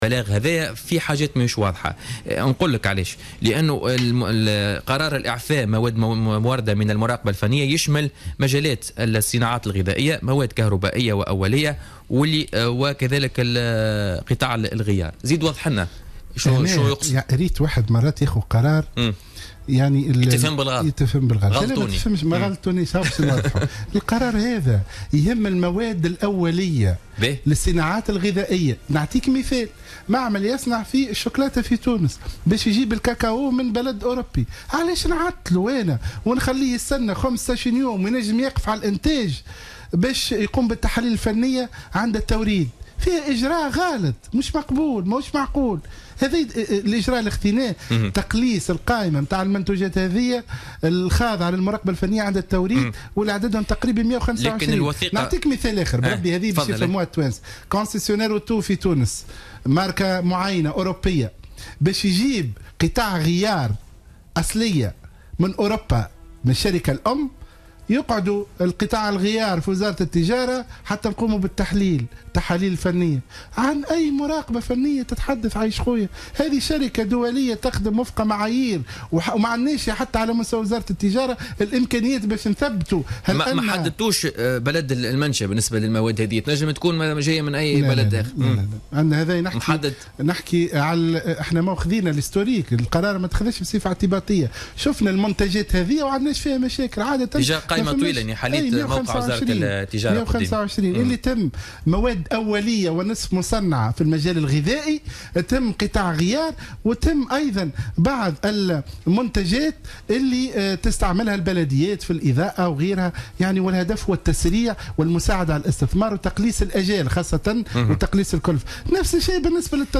أكد وزير التجارة محسن حسن ضيف بوليتيكا اليوم الجمعة 11 مارس 2016 أن قرار اعفاء على المراقبة الفنية لا تهم مواد استهلاكية وإنما تهم مواد أولية في الانتاج لتحريك عجلة الاقتصاد على حد قوله.